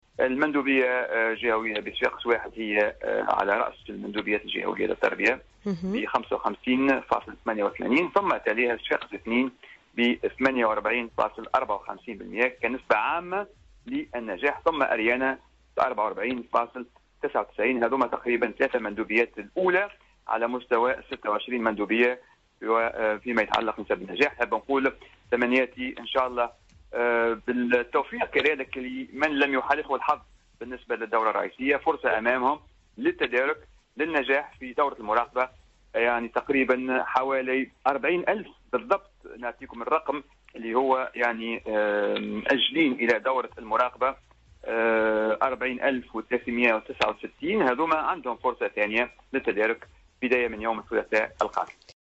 و قال الولباني في تصريح للجوهرة "أف أم" إنّ مندوبية التربية بصفاقس 1 تصدرّت القائمة بنسبة 55.88 %،فيما تحصلت المندوبية الجهوية للتربية بصفاقس2 على 48.54 %، فيما كان المركز الثالث للمندوبية الجهوية للتربية بأريانة بنسبة 44.99 % من النسب العامة للنجاح.